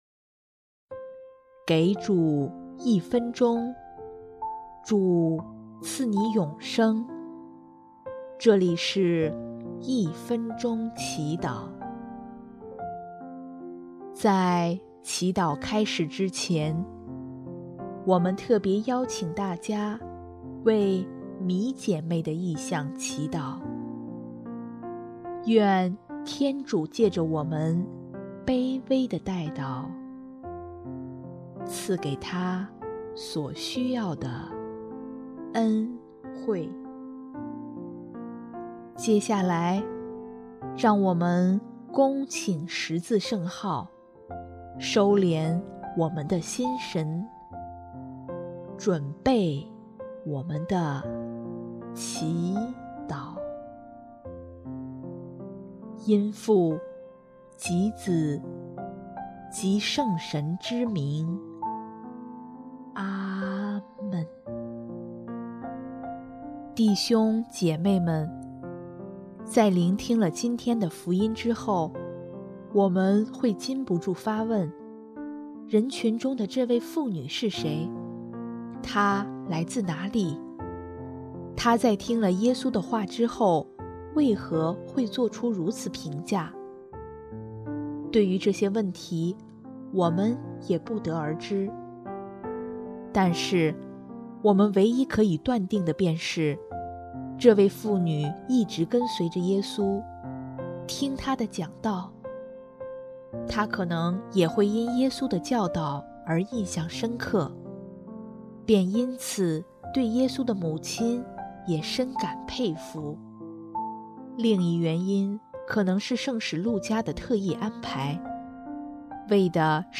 音乐：主日赞歌《如果你们爱我》